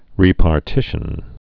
(rēpär-tĭshən)